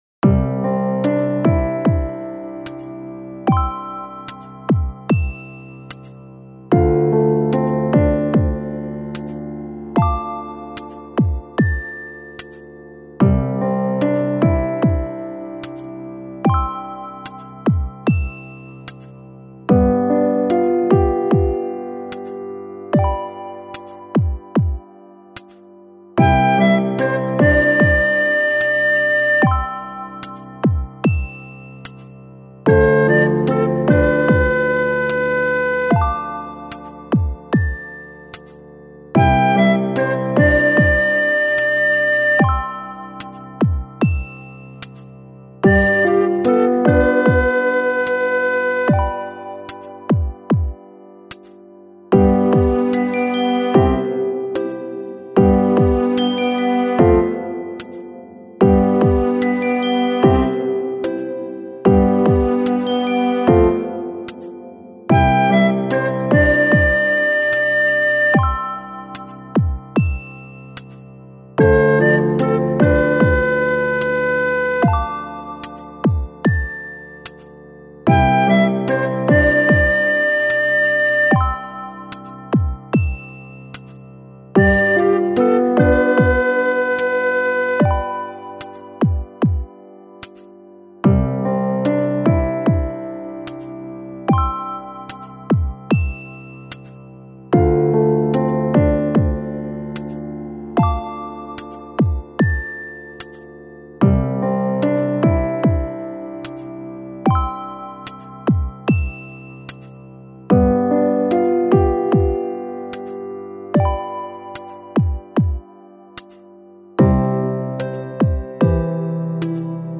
のんびりした雰囲気を持った、みんなが眠りについた後の様な、夜の日常BGMです。
ほのぼのした雰囲気のコンテンツをイメージしています。